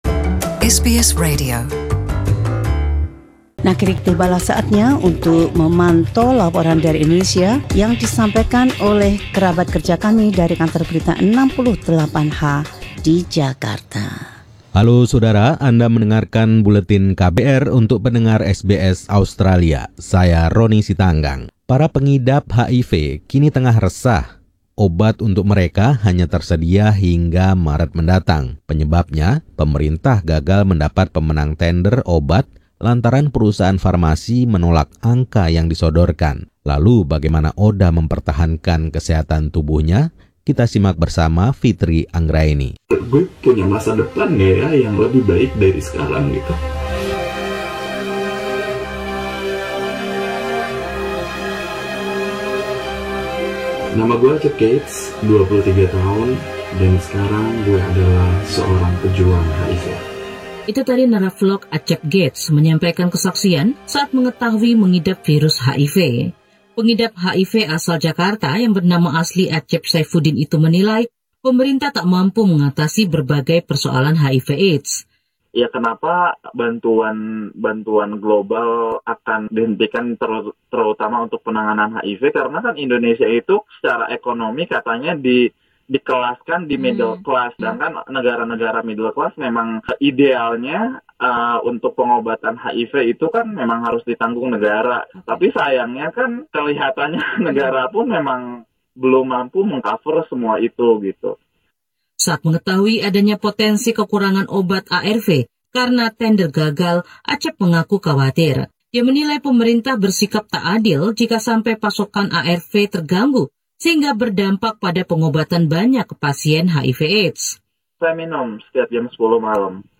This report from the team at KBR 68H examines the growing fears among Indonesian ODHA (people with HIV/AIDS) about the availability of their medication.